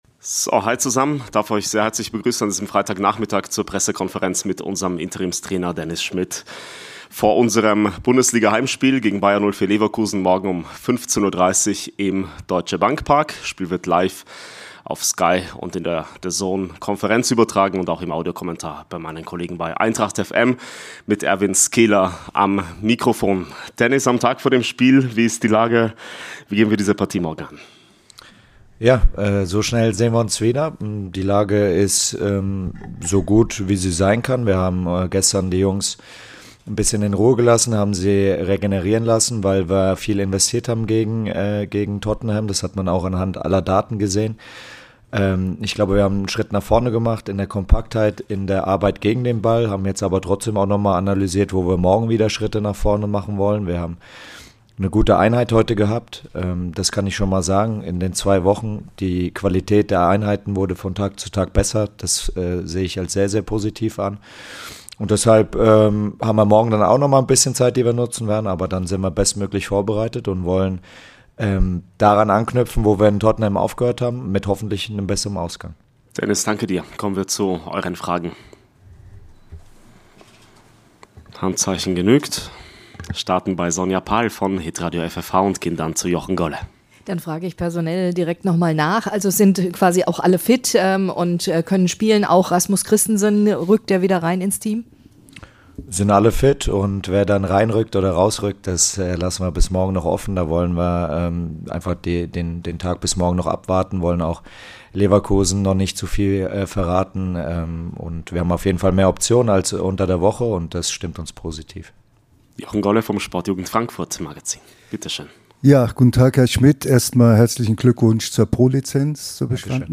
Die Pressekonferenz vor unserem Bundesliga-Heimspiel gegen Bayer 04 Leverkusen